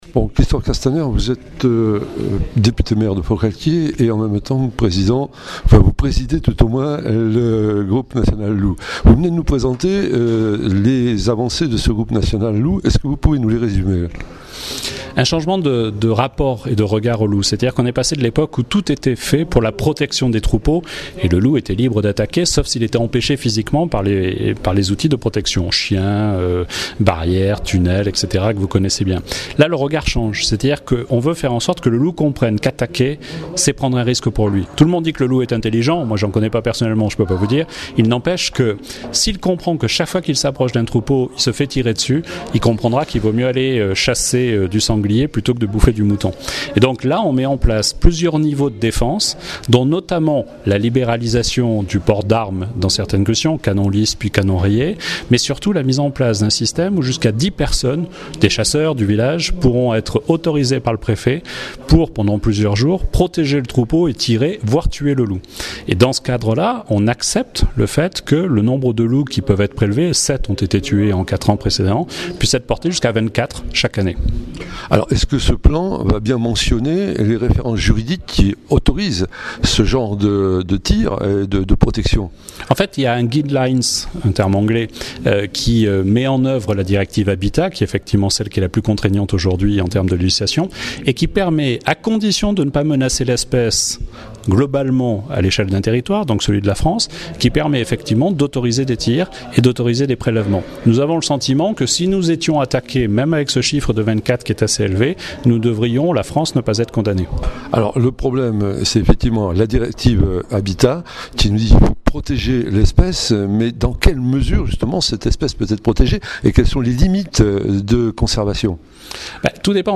- Christophe Castaner le 8 avril 2013 à l'AG d'Eleveurs et Montagnes à Forcalquier:
Interview Christophe Castaner